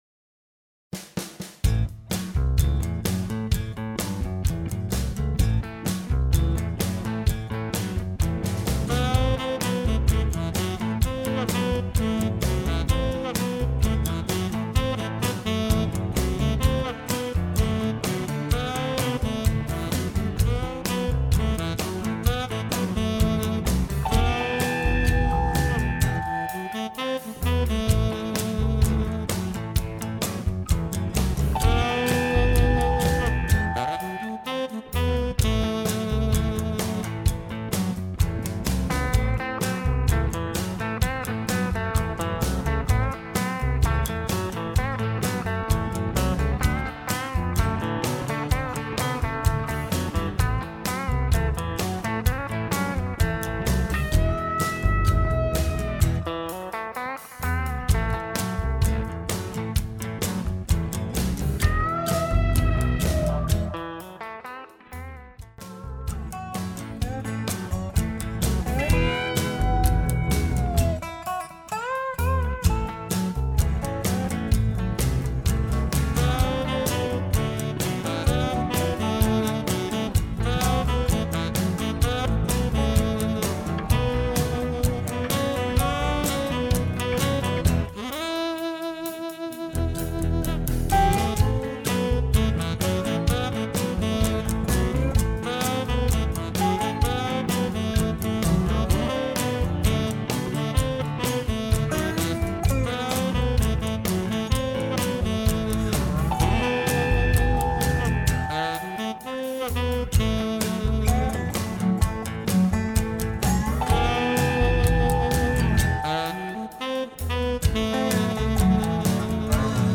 Instrumental TRacks
Without Screams